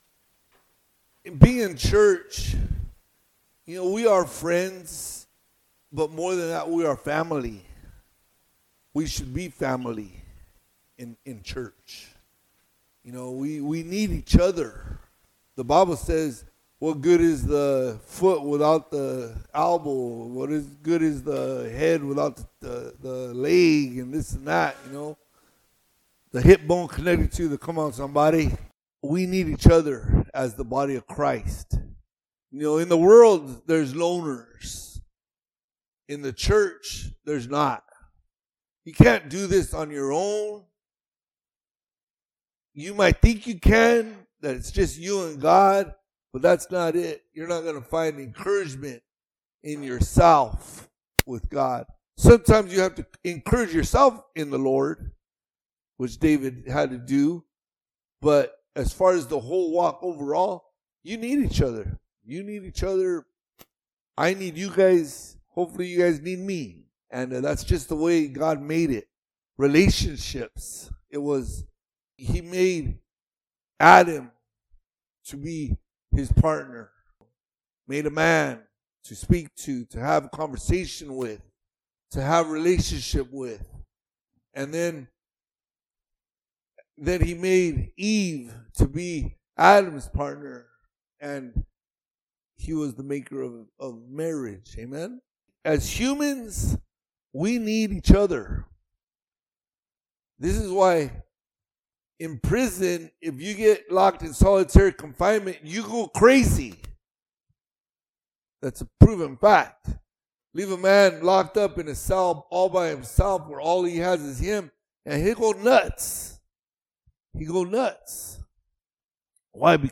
Podcast (piru-community-church-sermons): Play in new window | Download